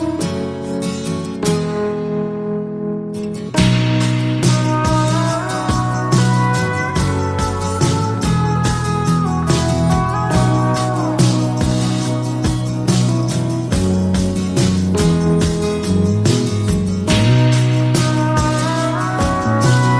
# happy